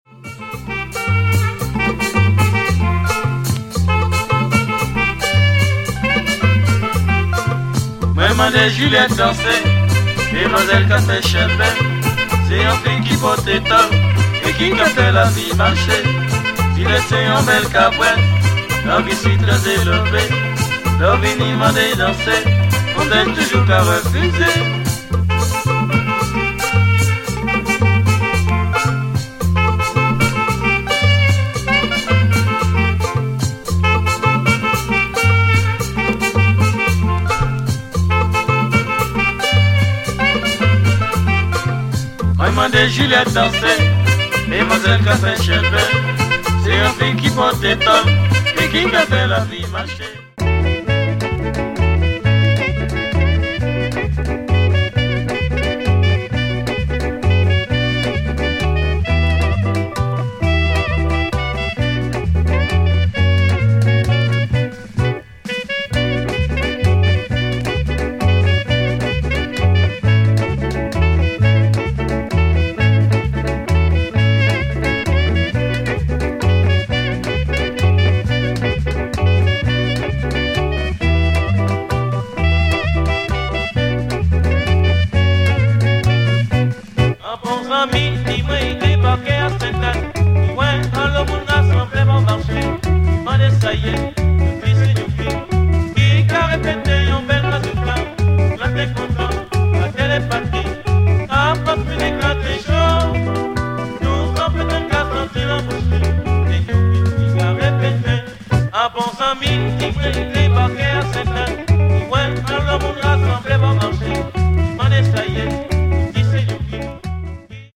HomeWorld MusicLatin  >  Salsa / Pachanga / Mambo / …
Biguines and Compas